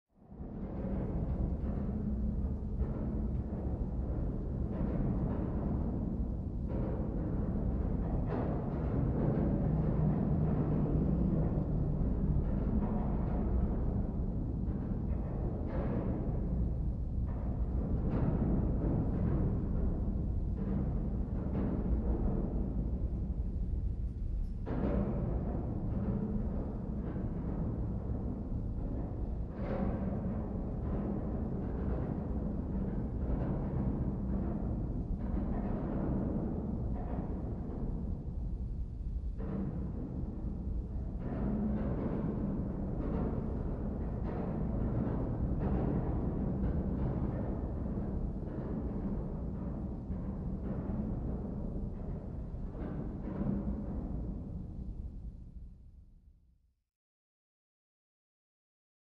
Submarine Ambience
Ship Moans And Dull, Metal Wronks From Deep Hull Point of View.